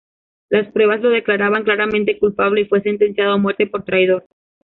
trai‧dor
/tɾaiˈdoɾ/